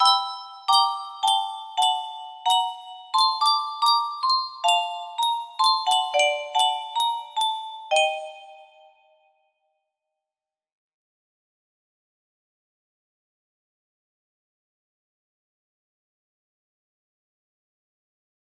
Testing music box melody